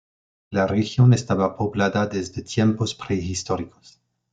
po‧bla‧da
/poˈblada/